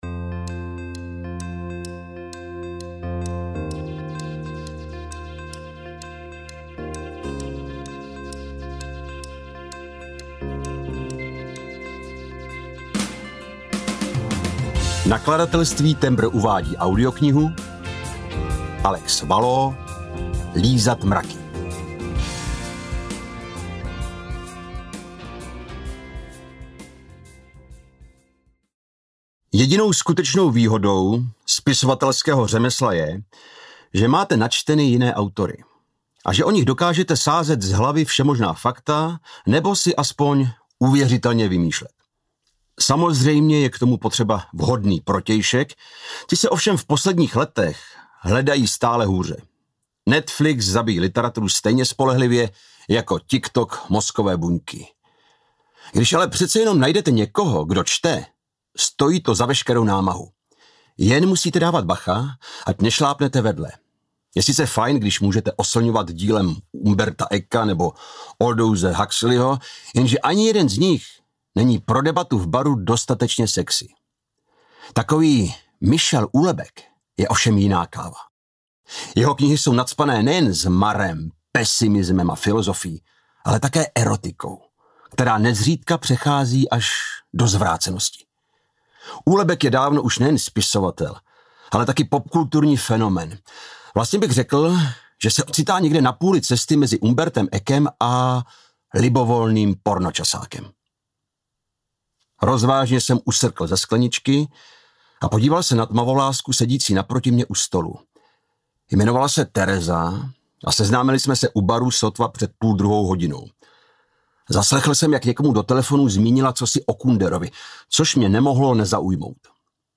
Lízat mraky audiokniha
Ukázka z knihy
Natočeno ve studiu NAPA Records s.r.o.